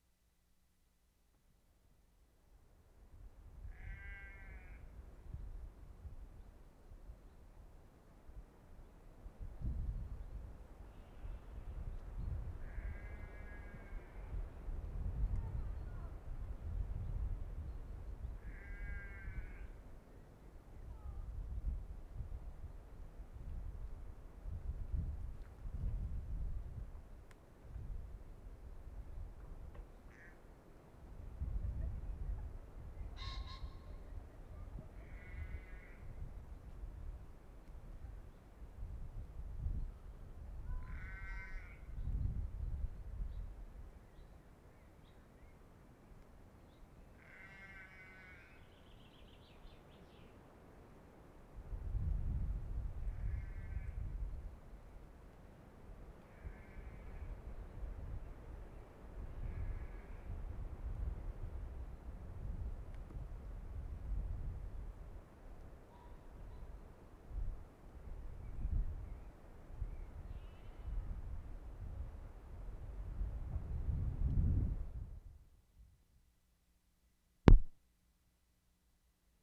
F 13-16. MALHAM TARN BIRD SANCTUARY
Same with human voices and sheep in background.